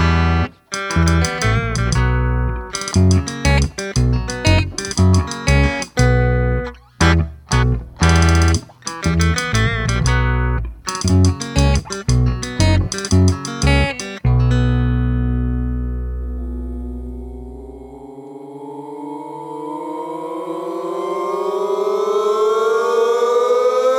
No Drums With Backing Vocals Comedy/Novelty 2:49 Buy £1.50